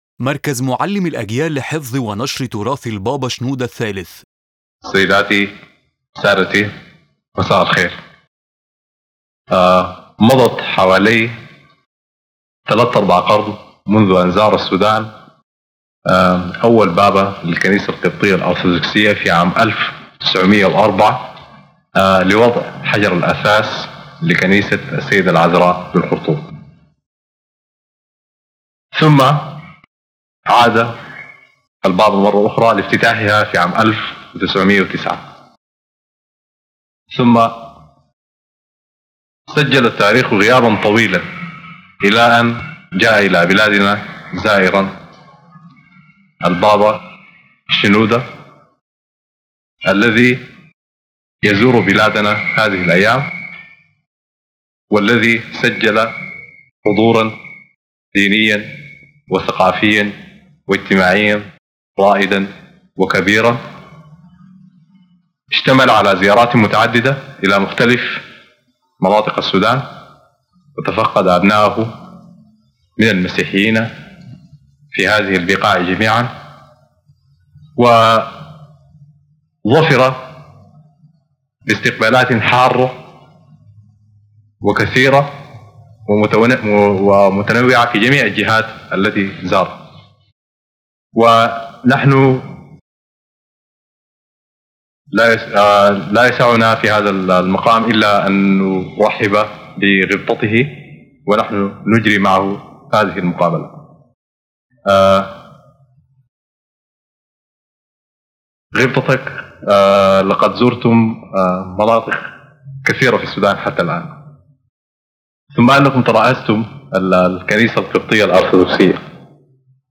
Pope Shenouda III’s interview with Sudanese television